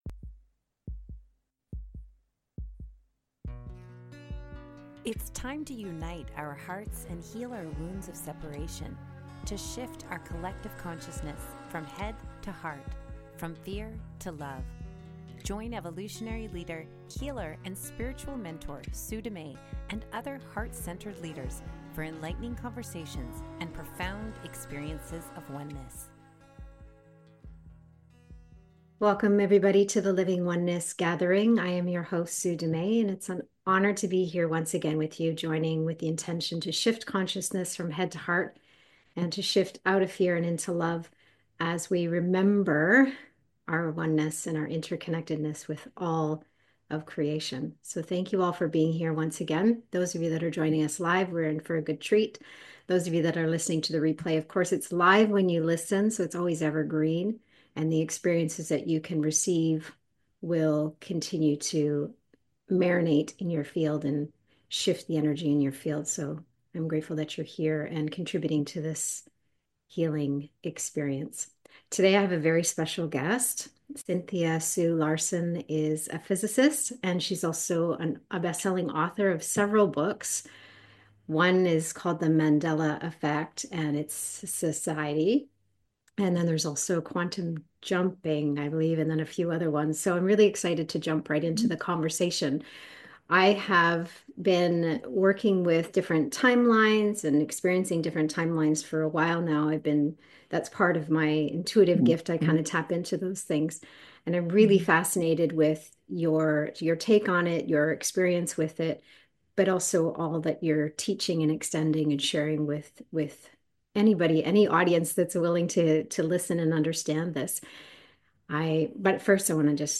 Would you like to learn how to navigate the polarizing chaos in the world without getting pulled down the wormhole of fear? This guided journey and experience will empower us to hold steady in the still point of our heart center.
This gathering was recorded LIVE on Jan 23rd, 2025